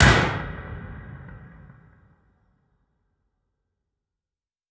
Gunshot Sound Effect Free Download
Gunshot